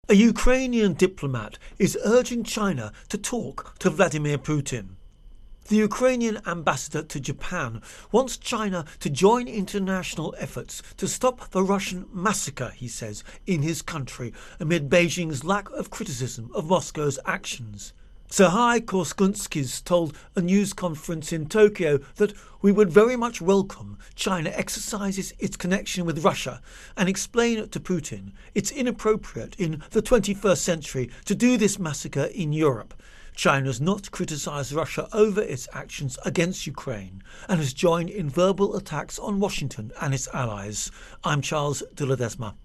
Ukraine-Invasion-Japan-China Intro and Voicer